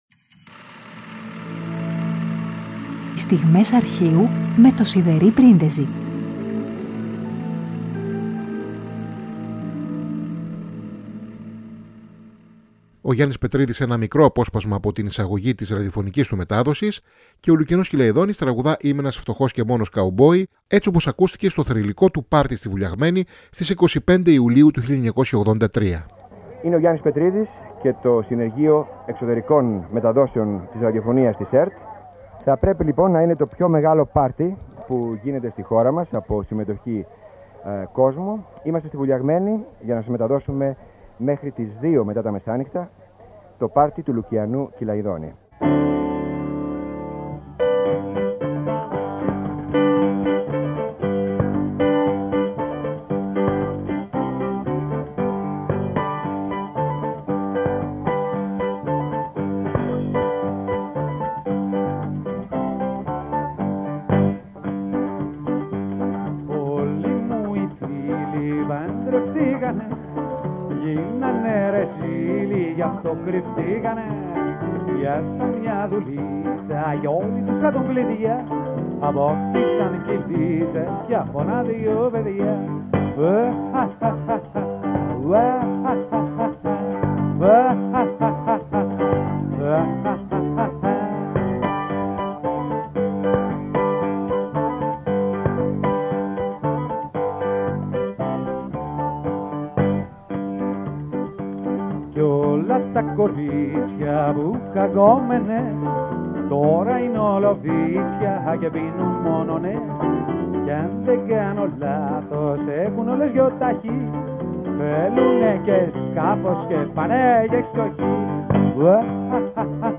Πέμπτη 28 Απριλίου: Ο Γιάννης Πετρίδης σε ένα μικρό μέρος από τη ραδιοφωνική του μετάδοση και ο Λουκιανός Κηλαηδόνης τραγουδά «Είμαι ένας φτωχός και μόνος κάου-μπόυ» από το θρυλικό του πάρτυ στη Βουλιαγμένη στις 25 Ιουλίου 1983.